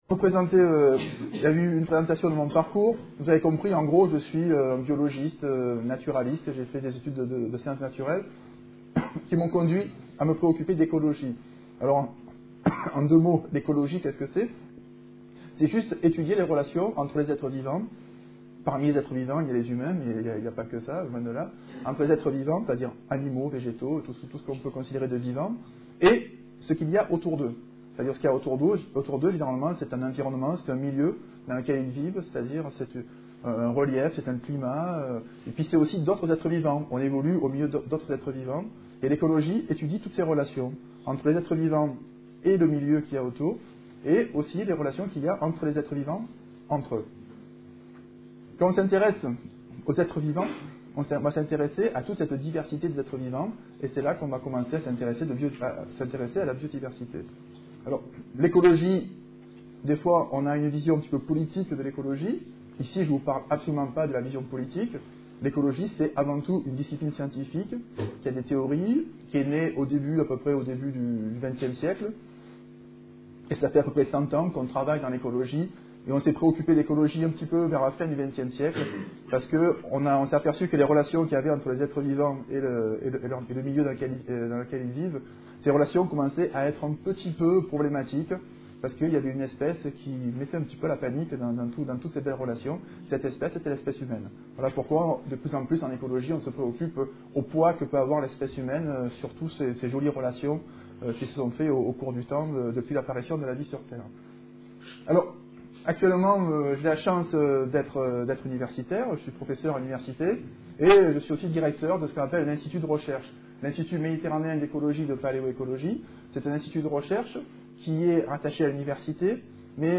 Une conférence de l'UTLS au Lycée La biodiversité